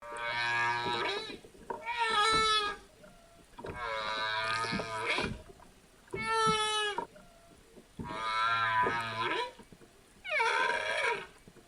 Free Animals sound effect: Whale Song Dry.
Whale Song Dry
Whale Song Dry.mp3